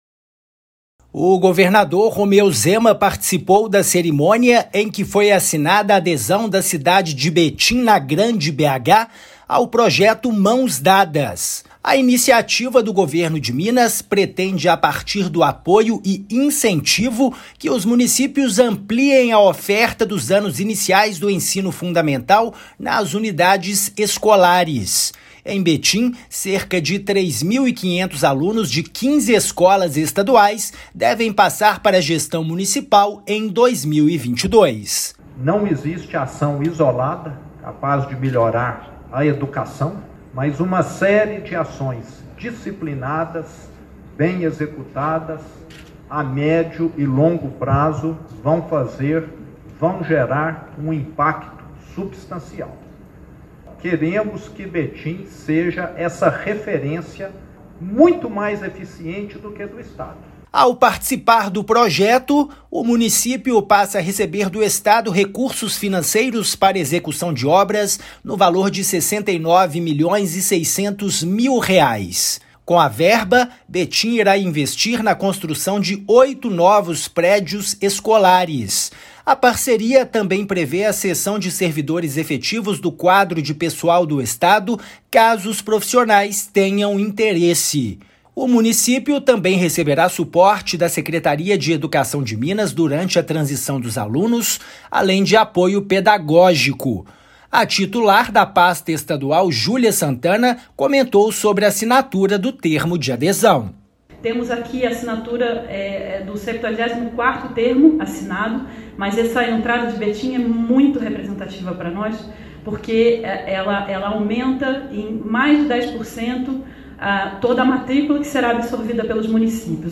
Estado irá repassar ao município recursos para a construção de oito novos prédios escolares; cerca de 3.500 alunos de 15 escolas estaduais passarão para a gestão municipal em 2022. Ouça a matéria de rádio.
MATÉRIA_RÁDIO_GOVERNADOR_BETIM.mp3